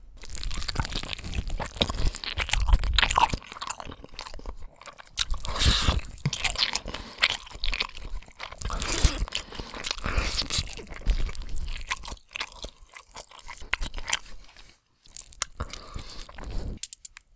Eeating-juicy-meat.wav